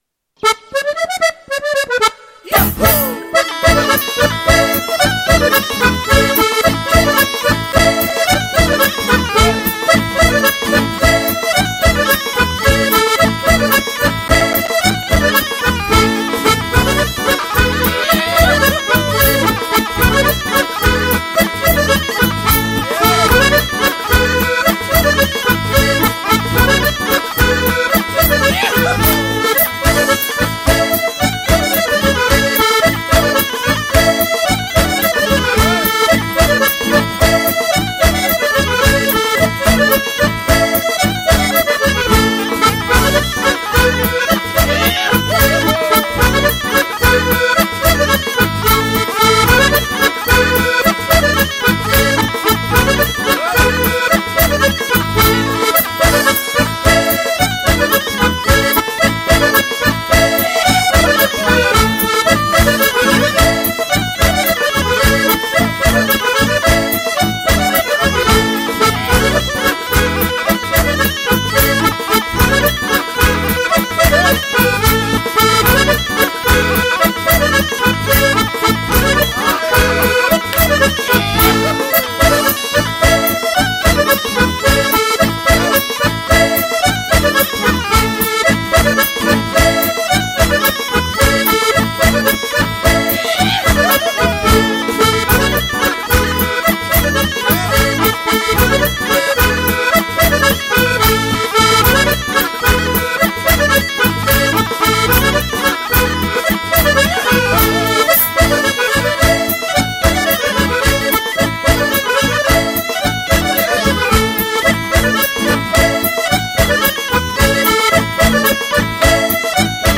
la bourrée.mp3